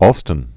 (ôlstən), Washington 1779-1843.